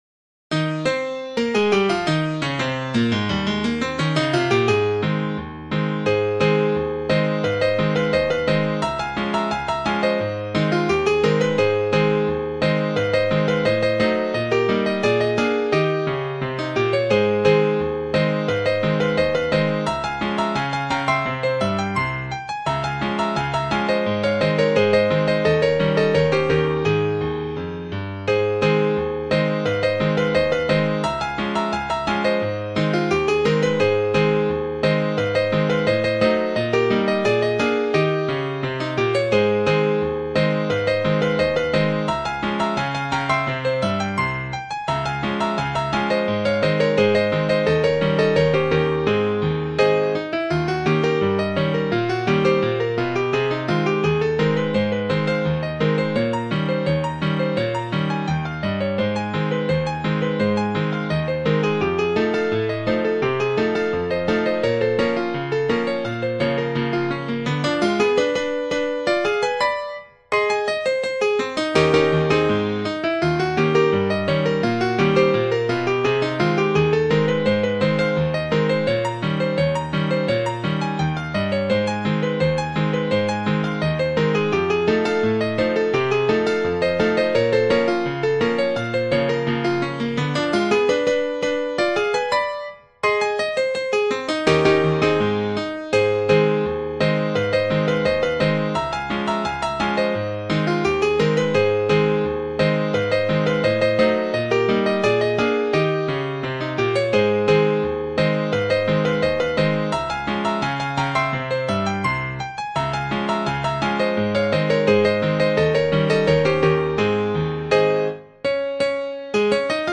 a piano rag
the best known composer of ragtime.